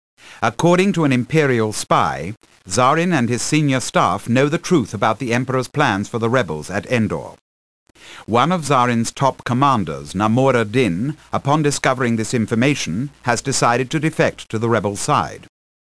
―An Imperial briefing officer — (audio)
BriefingOfficer-DinRebels-TIEFighter.ogg